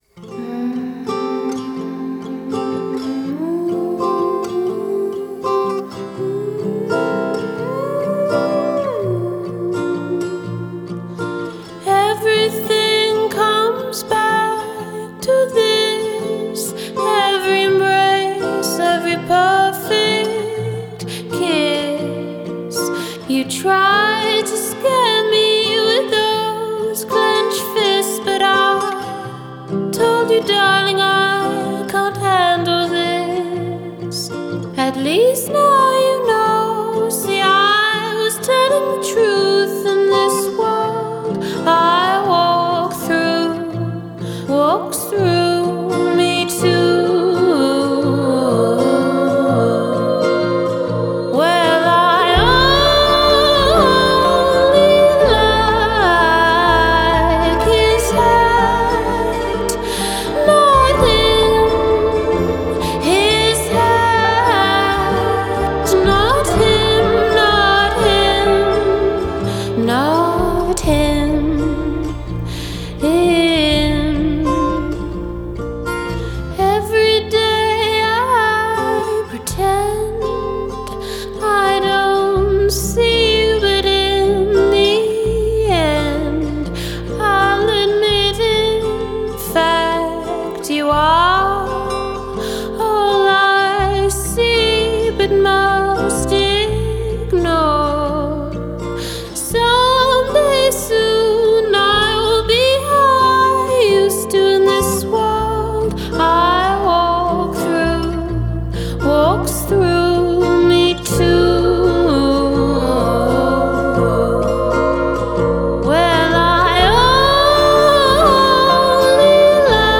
Genre: Indie Folk